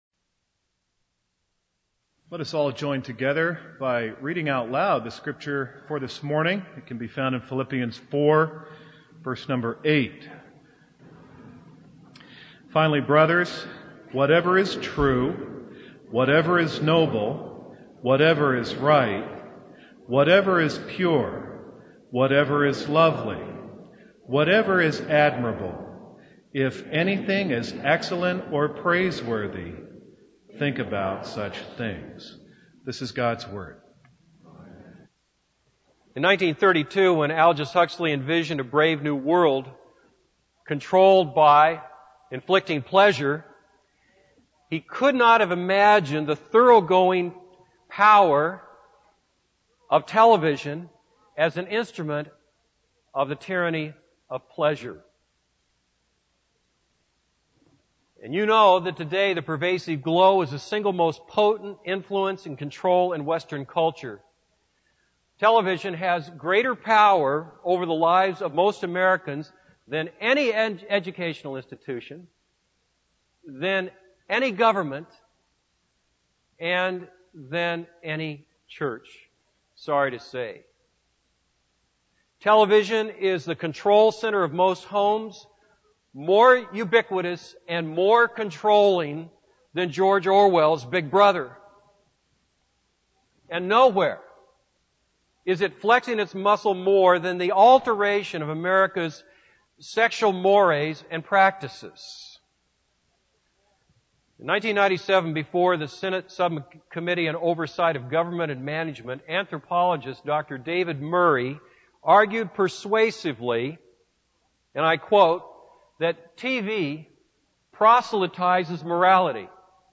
This is a sermon on Philippians 4:8.